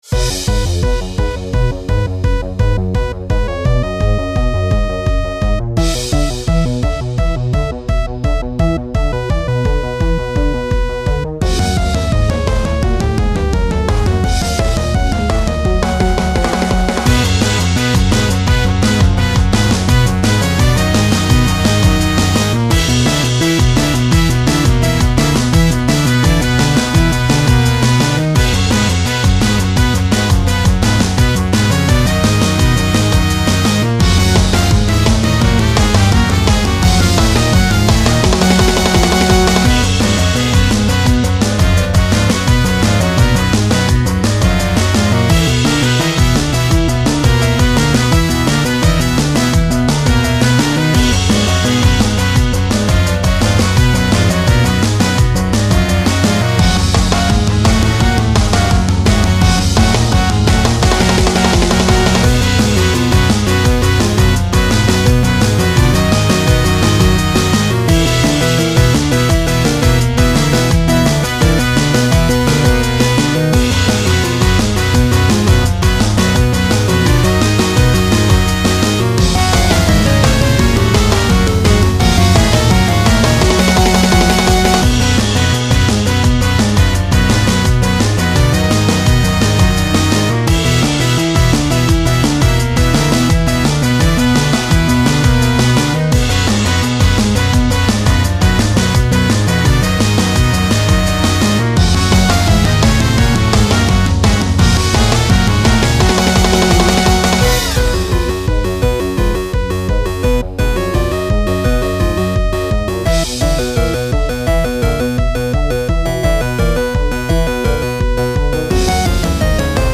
【コミカル系BGM10】
【用途/イメージ】　チャレンジ　面白い　オチ　アニメetc